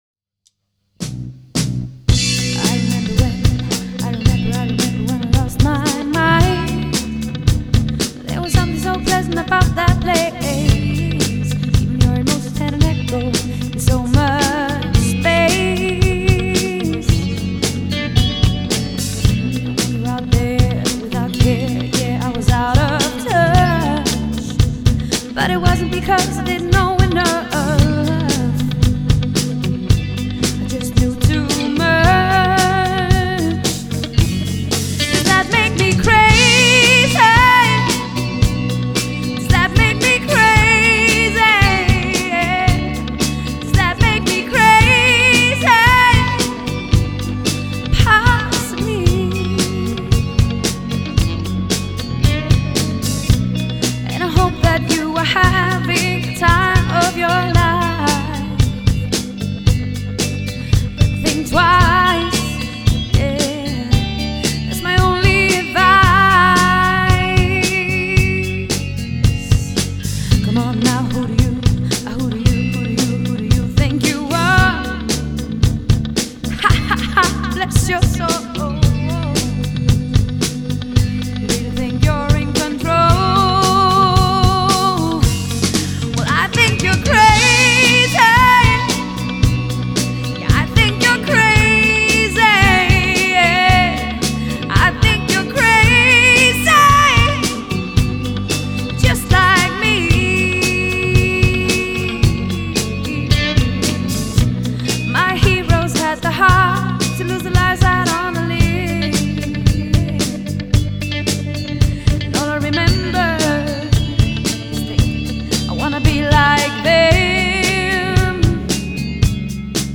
• Allround Partyband
• Coverband